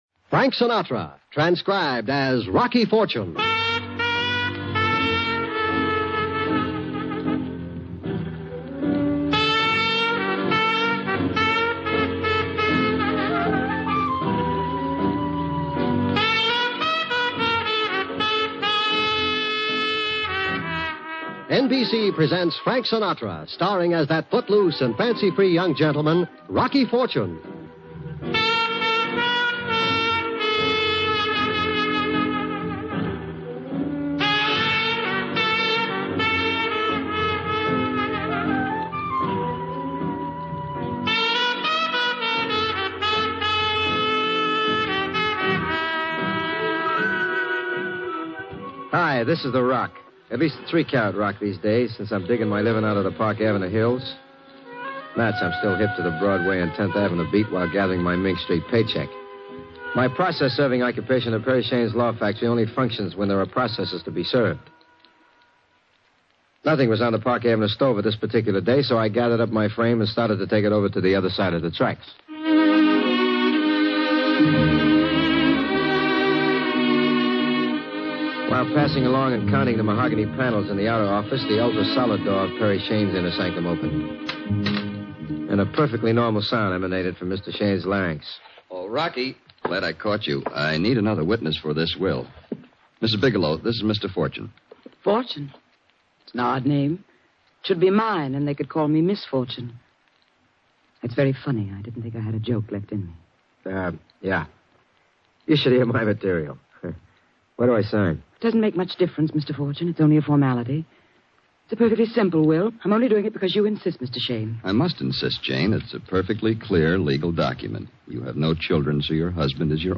Rocky Fortune, Starring Frank Sinatra